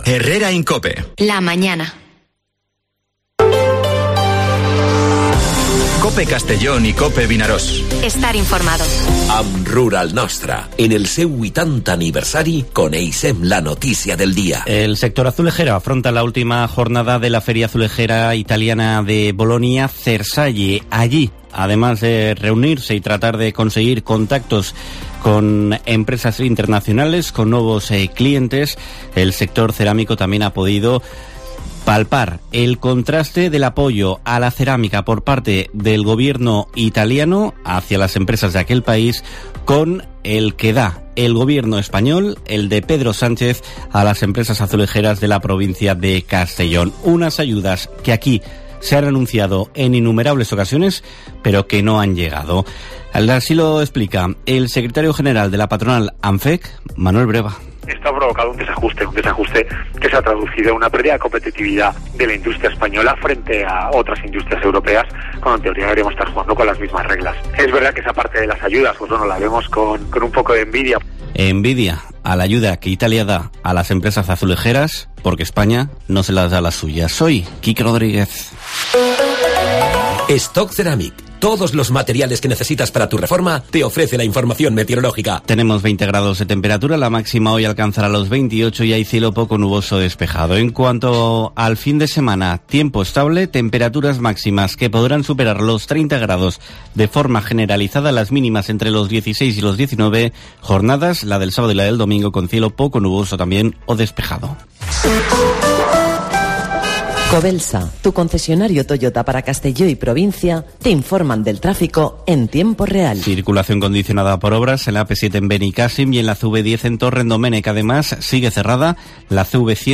Informativo Herrera en COPE en la provincia de Castellón (29/09/2023)